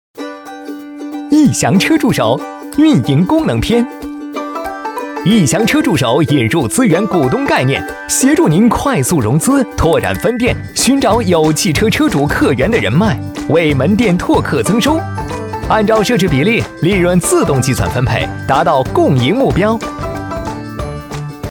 配音老师